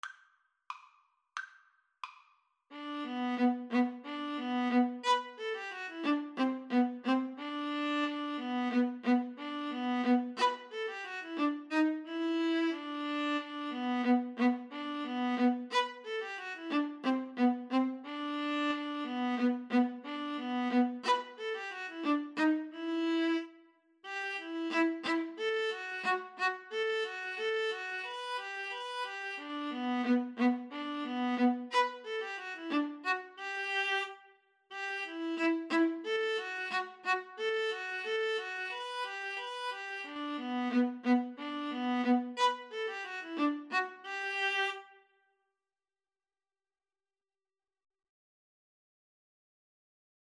Allegro risoluto = c.90 (View more music marked Allegro)
2/4 (View more 2/4 Music)
Classical (View more Classical Viola Duet Music)